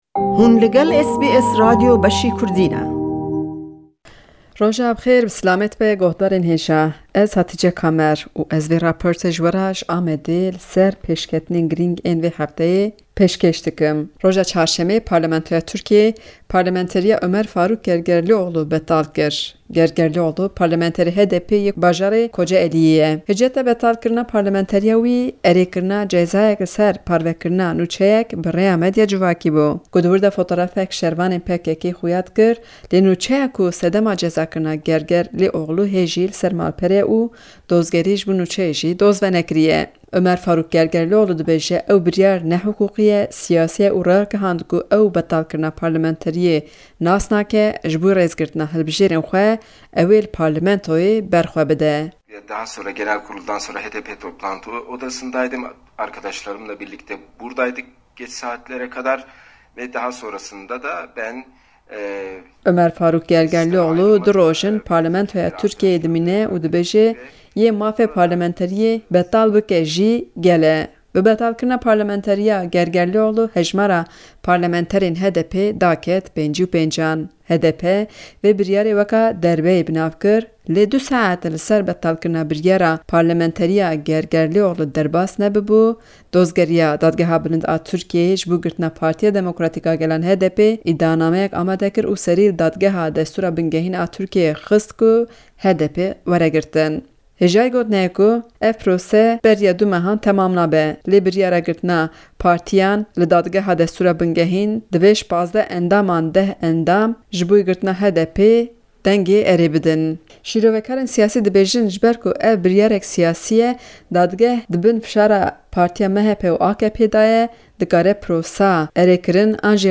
Raporta vê hefte yê ji Amedê li ser doza girtina HDPê û betalkirina parlamenteriya parlamenterê HDP Omer Faruk Gergerlioglu ye. Serdozgerê Dadgeha Bilind ya Tirkiye bi hiceta ku HDP dewamiya PKK ye, bi fermana wan siyasetê dike, li Dadgeha Destûra Bingehîn doz vekir.